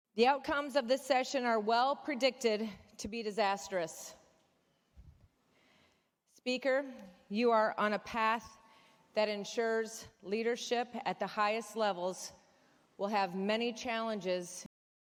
In her floor speech before adjournment, House Minority Leader Tony McCombie said the policies majority Democrats approved will have consequences.